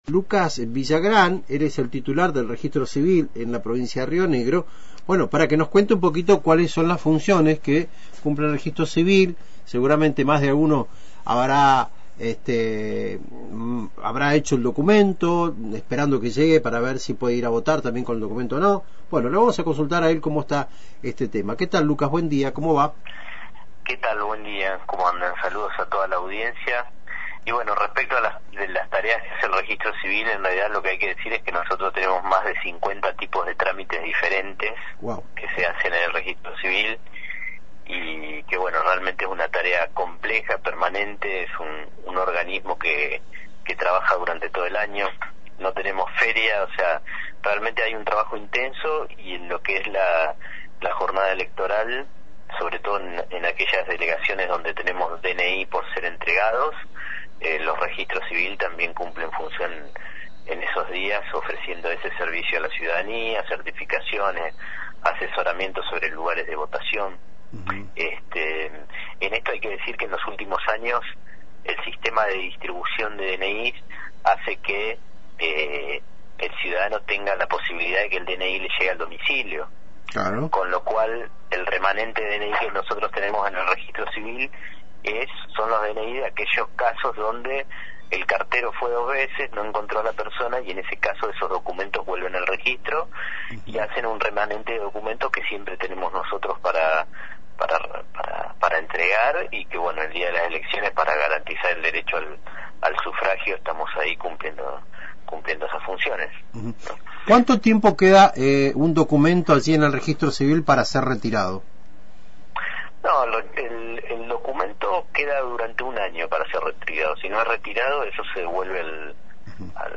Conversamos con Lucas Villagran, titular del registro civil en Río Negro, para conversar sobre las funciones que llevarán a cabo durante la jornada de las elecciones.